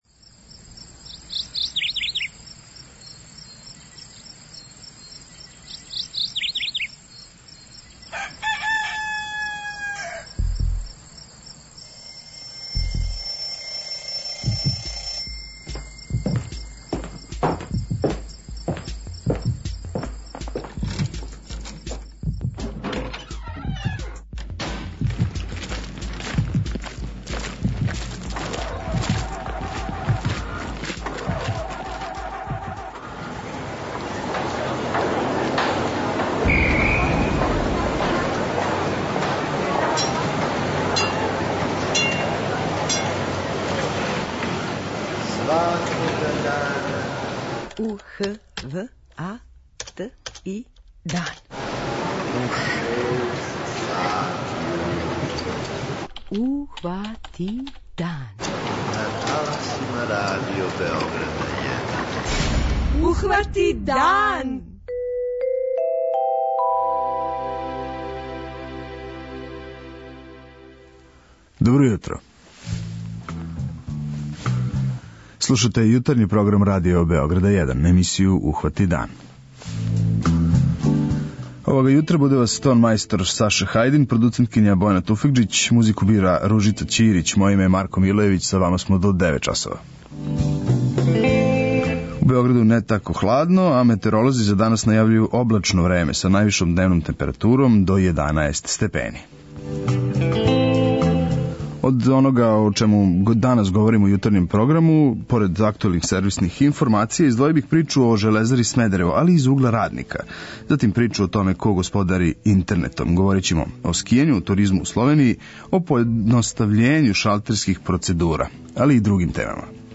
Данас ћемо, дакле, чути раднике Железаре Смедерево.